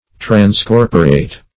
Search Result for " transcorporate" : The Collaborative International Dictionary of English v.0.48: Transcorporate \Trans*cor"po*rate\, v. i. [Pref. trans- + corporate.] To transmigrate.